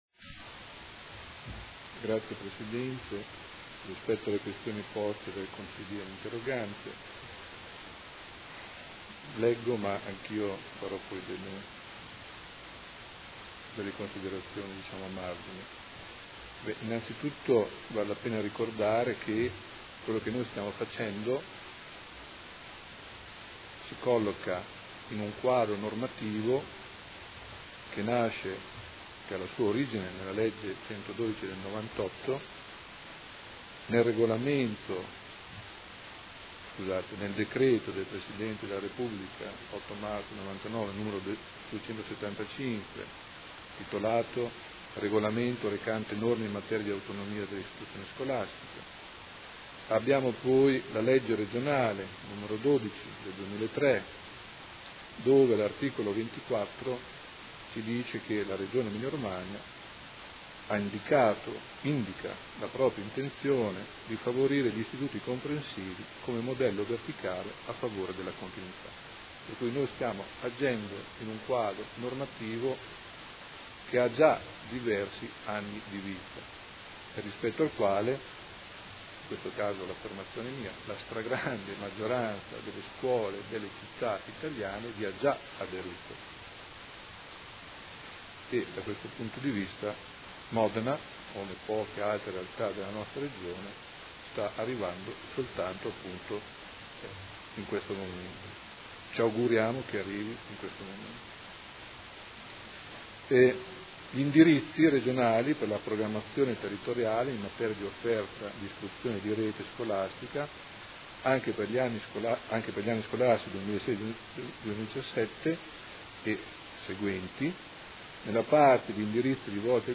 Seduta del 5/11/2015. Interrogazione del Consigliere Rocco (FaS) avente per oggetto: Ridimensionamento rete scolastica. Risponde l'assessore